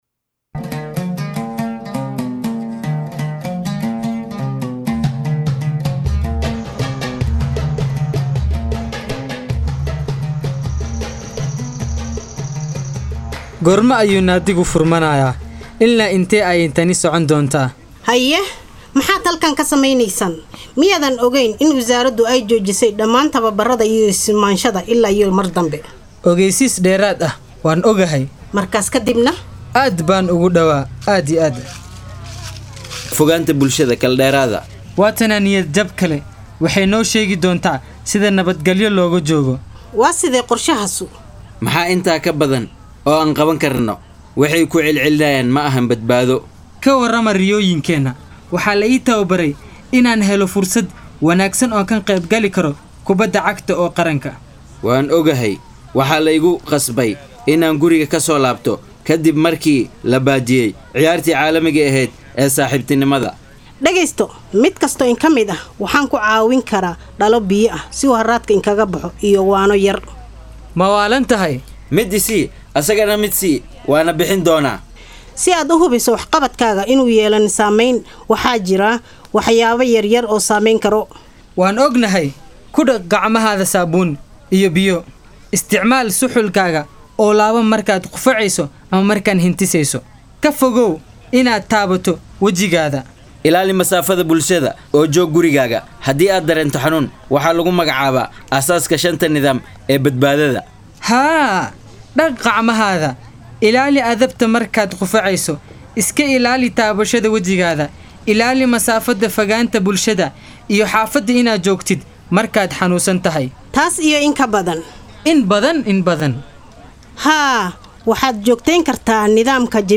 Somali play on the impacts of covid-19 in sports.